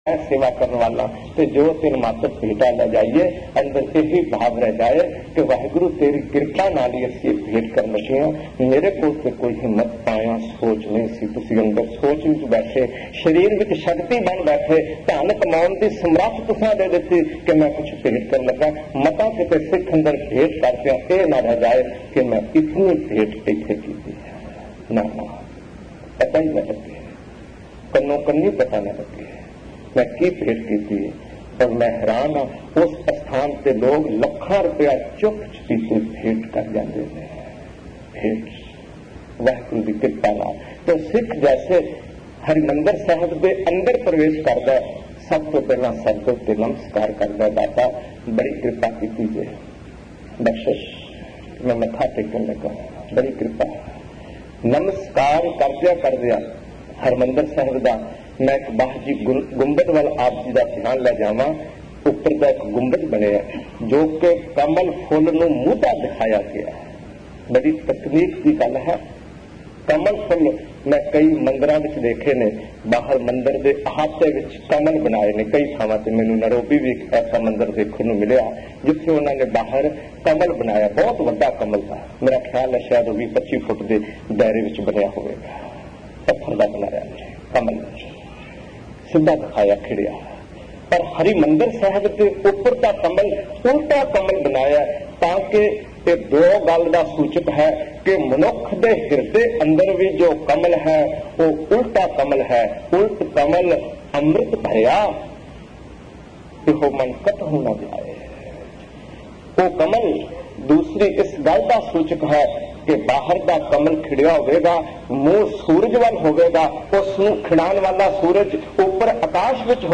Amritsar Sifti Da Ghar Genre: Gurmat Vichaar(Katha) Album Info